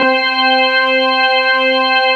23 ORGAN  -R.wav